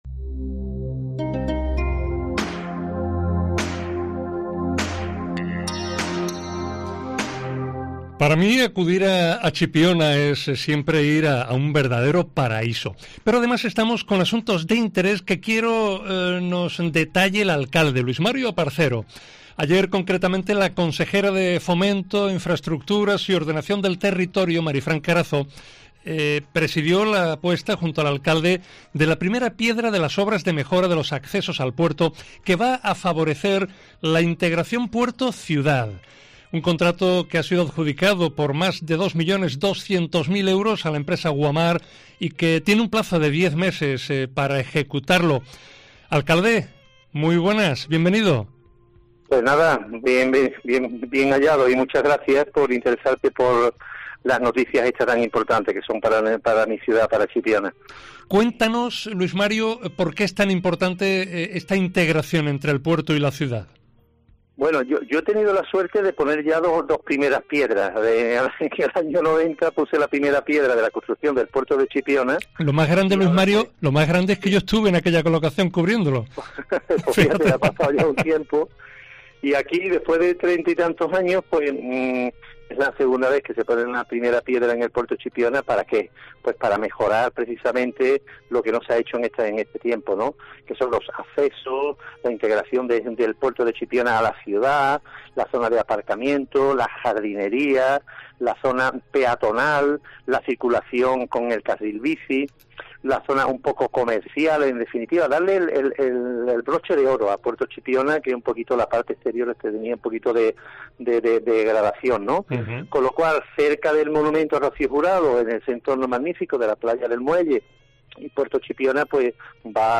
El alcalde Luis Mario Aparcero explica su apuesta por la seguridad y valora compromisos de Fomento como las obras de integración puerto-ciudad inauguradas el lunes